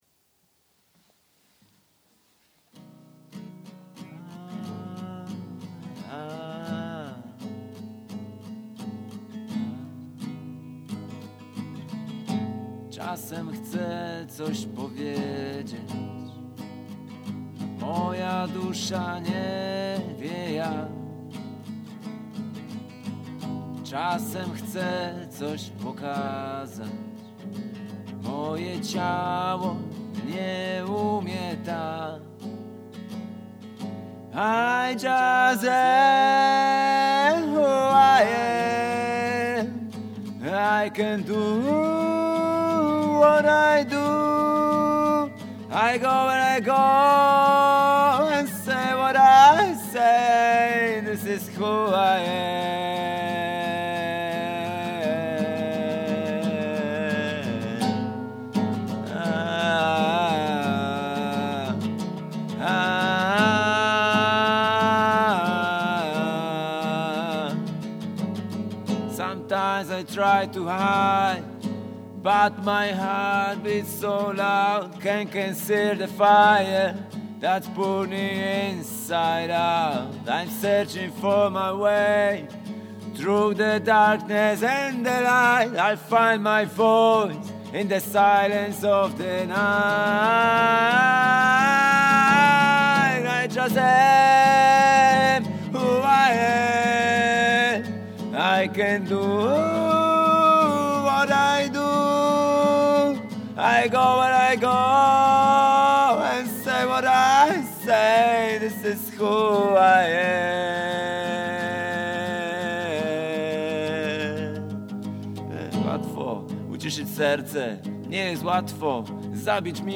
artist & one-person music band
Recorded in one take – with no cuts or edits.